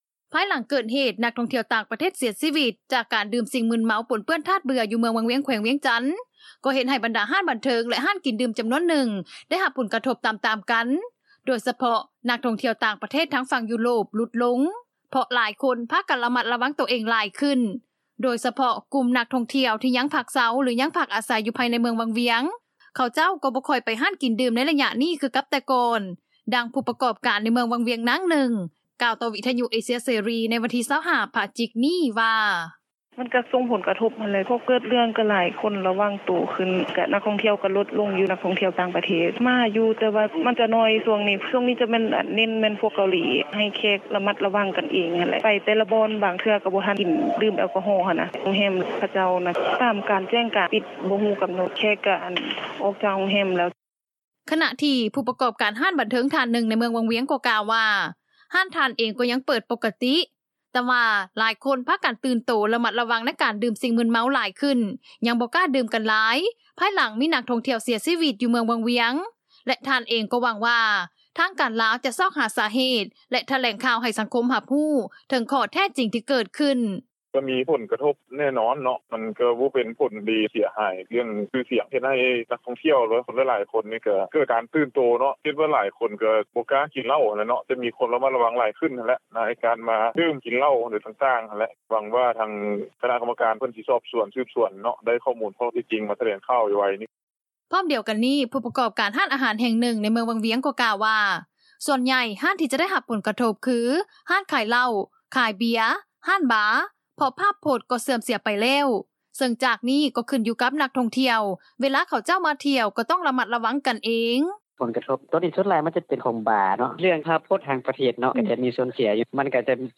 ພາຍຫຼັງເກີດເຫດ ນັກທ່ອງທ່ຽວຕ່າງປະເທດເສຍຊີວິດ ຈາກການດື່ມສິ່ງມຶນເມົາ ປົນເປື້ອນທາດເບື່ອ ຢູ່ເມືອງວັງວຽງ ແຂວງວຽງຈັນ ກໍເຮັດໃຫ້ບັນດາຮ້ານບັນເທີງ ແລະຮ້ານກິນດື່ມຈໍານວນໜຶ່ງ ໄດ້ຮັບຜົນກະທົບຕາມໆກັນ, ໂດຍສະເພາະ ນັກທ່ອງທ່ຽວຕ່າງປະເທດ ທາງຝັ່ງຢຸໂຣບ ຫຼຸດລົງ, ເພາະຫຼາຍຄົນ ພາກັນລະມັດລະວັງໂຕເອງຫຼາຍຂຶ້ນ, ໂດຍສະເພາະ ກຸ່ມນັກທ່ອງທ່ຽວທີ່ຍັງພັກເຊົ່າ ຫຼື ຍັງພັກອາໄສຢູ່ໃນເມືອງວັງວຽງ, ເຂົາເຈົ້າ ກໍບໍ່ຄ່ອຍໄປຮ້ານກິນດື່ມ ໃນໄລຍະນີ້ ຄືກັບແຕ່ກ່ອນ, ດັ່ງຜູ້ປະກອບການ ໃນເມືອງວັງວຽງ ນາງໜຶ່ງ ກ່າວຕໍ່ວິທຍຸເອເຊັຽເສຣີ ໃນວັນທີ 25 ພະຈິກ ນີ້ ວ່າ